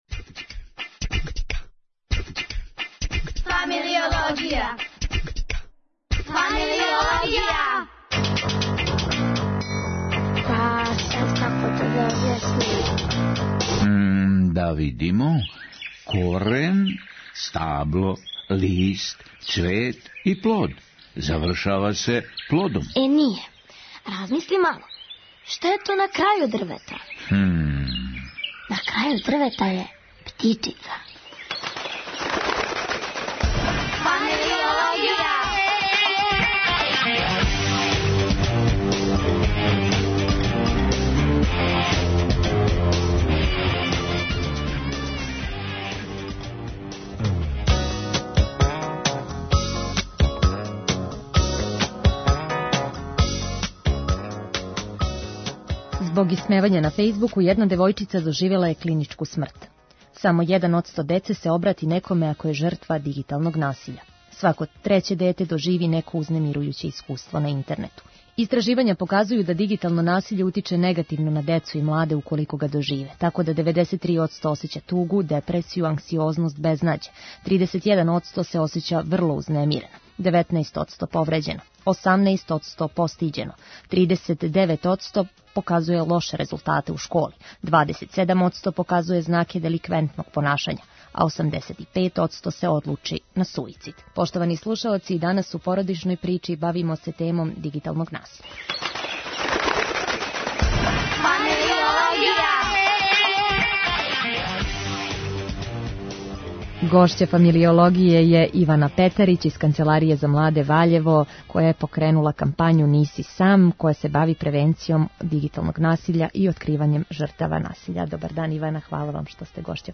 Питамо наше госте, због чега.